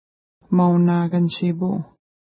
Pronunciation: ma:una:kən-ʃi:pu: